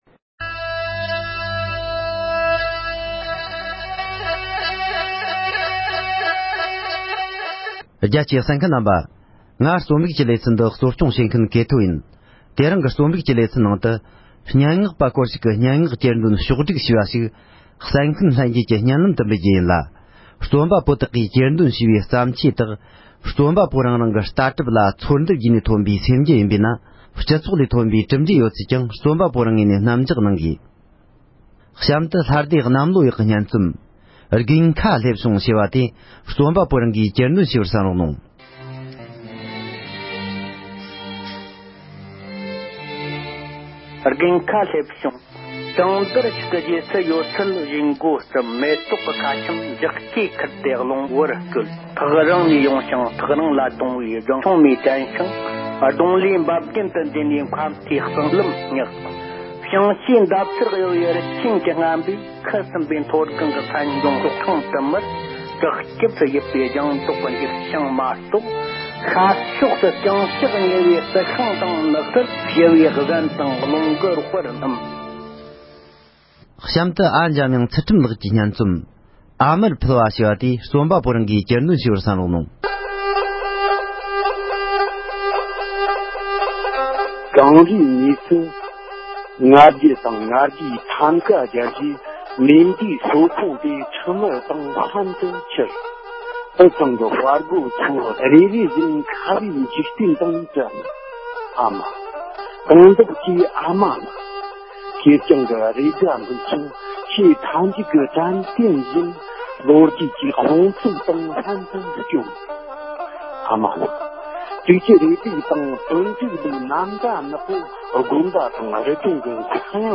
རྩོམ་པ་པོ་སྐོར་ཞིག་གི་སྙན་ངག་གྱེར་འདོན་ཕྱོགས་སྒྲིག་ཞུས་པ།